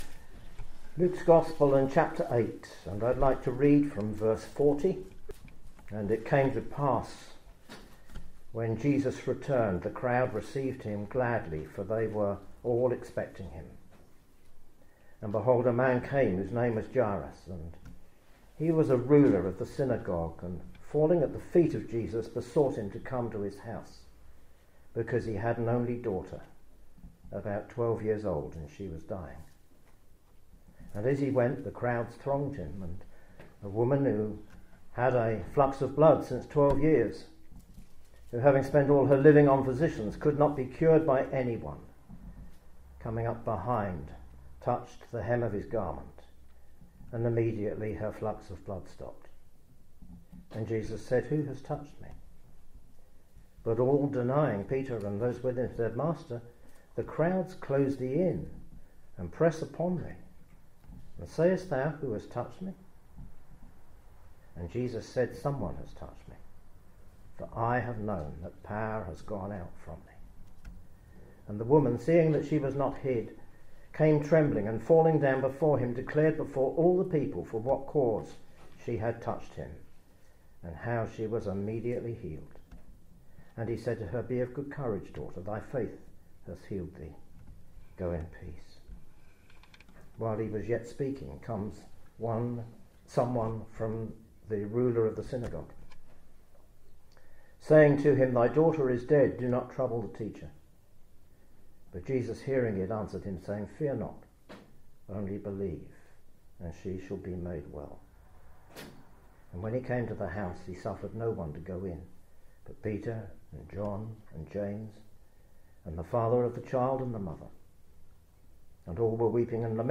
In this gospel message, you'll hear two stories of Jesus drawing near to sinners to meet their needs. We all need salvation because of our sins, and the Bible points us to God's Son, Jesus Christ, the Saviour of sinners.